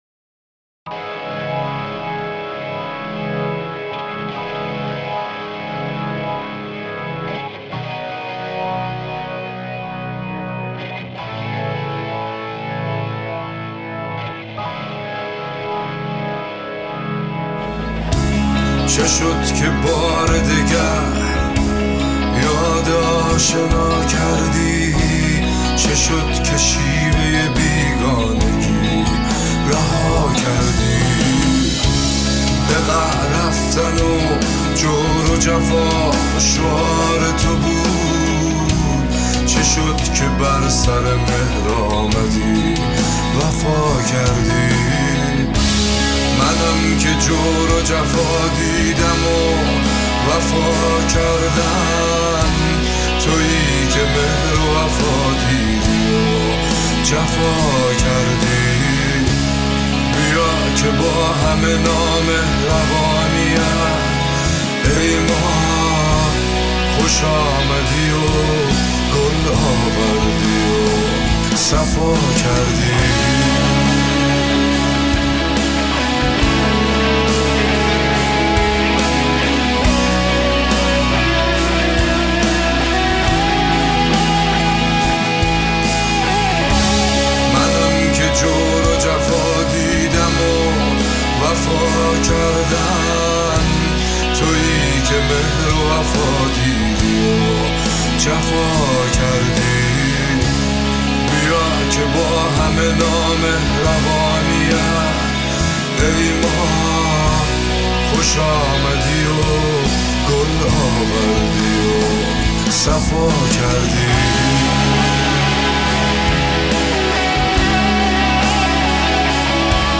Contemporary